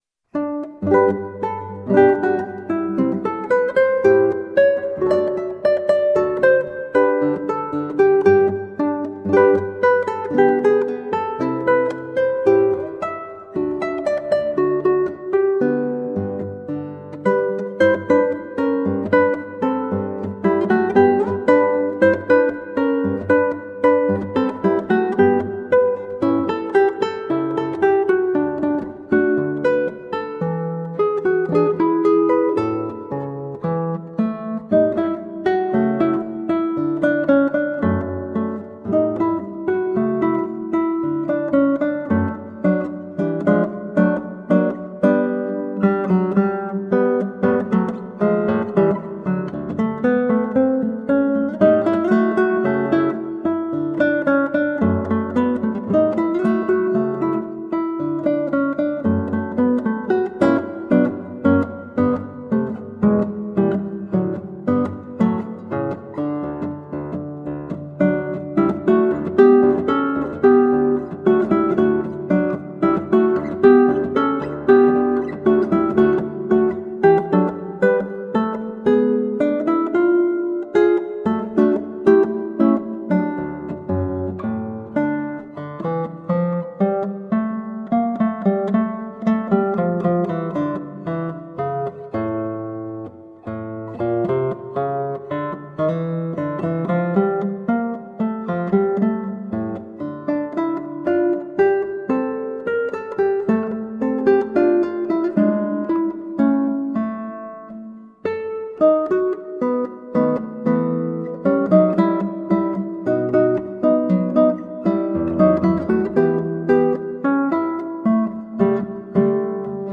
Colorful classical guitar.
performed on 8-string classical guitar, uses open G tuning.
changes to a solo rag